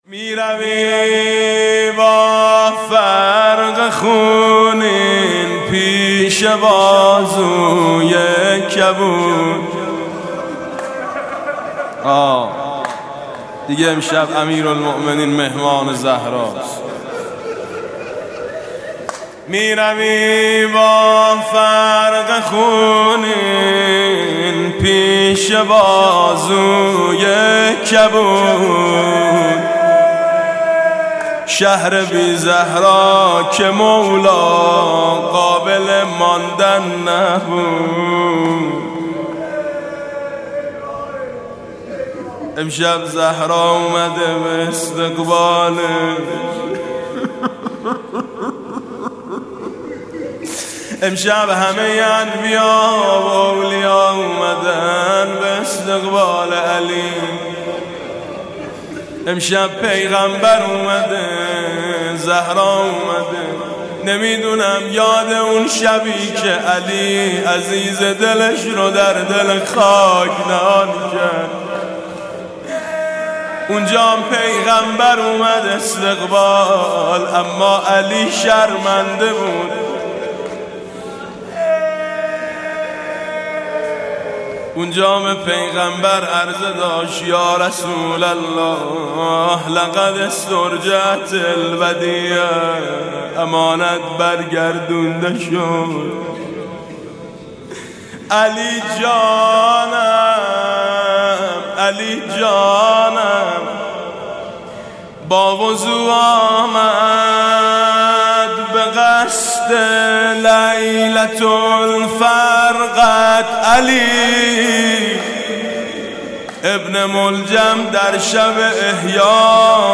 روضه حاج میثم مطیعی